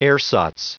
Prononciation du mot ersatz en anglais (fichier audio)
Prononciation du mot : ersatz